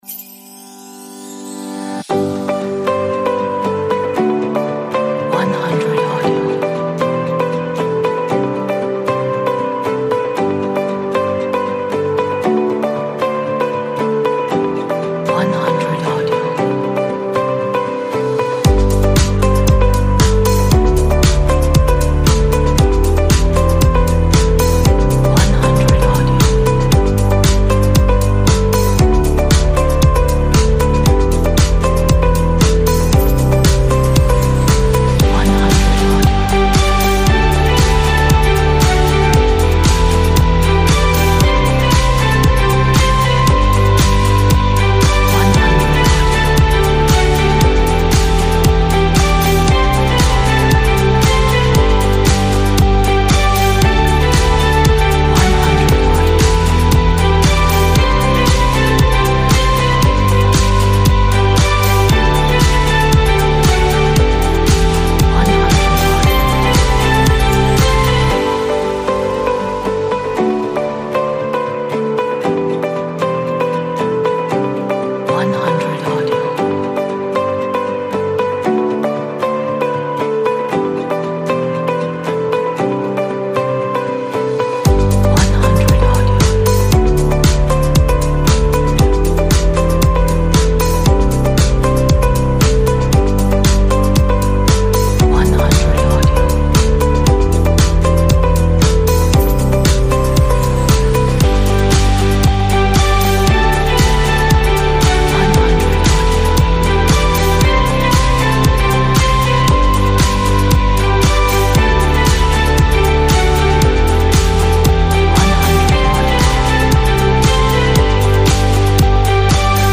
a nice corporate pop inspiring track